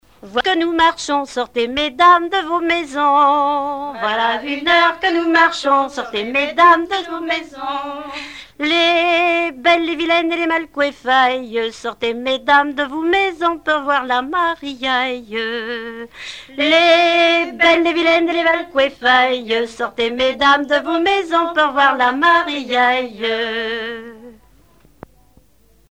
circonstance : fiançaille, noce
Genre énumérative
Chansons traditionnelles